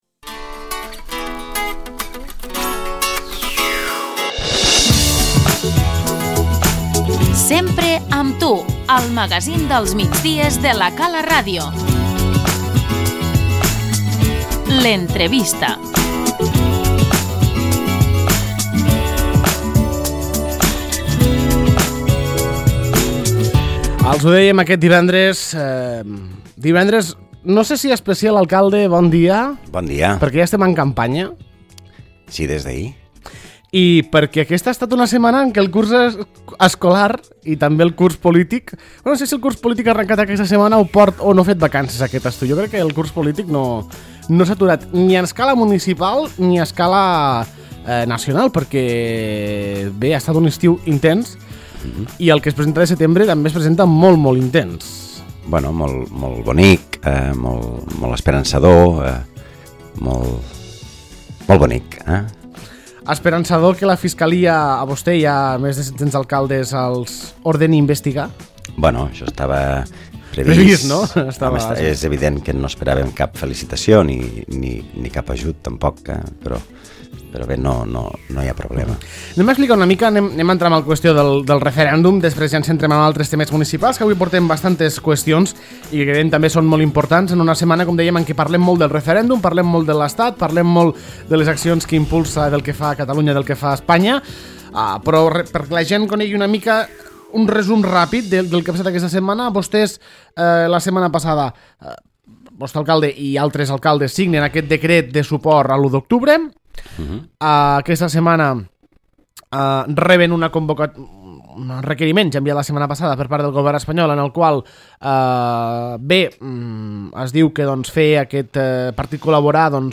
L'entrevista - Jordi Gaseni, alcalde de l'Ametlla de Mar